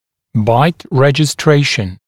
[baɪt ˌreʤɪ’streɪʃn] [байт ˌрэджи’стрэйшн] регистрация прикуса, оттиск регистрации прикуса